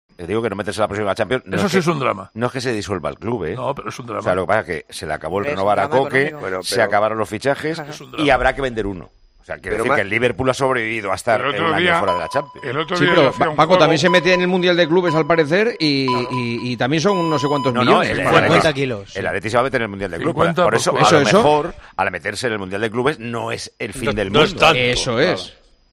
El director y presentador de Tiempo de Juego valora la situación actual del equipo rojiblanco tras la derrota ante el Barcelona de la semana pasada.